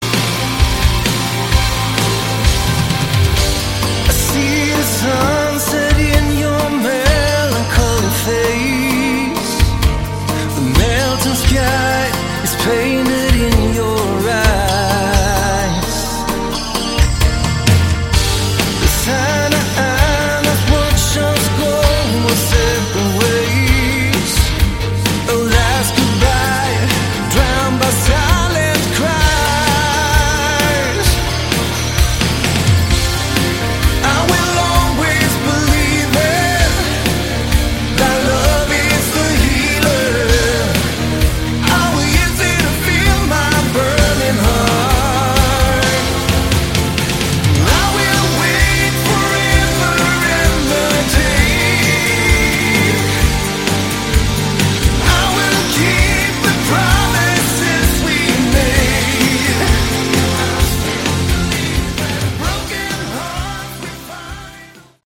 Category: Melodic Rock
lead and backing vocals, acoustic guitars
electric guitars
lead guitars
bass and backing vocals
keyboards
drums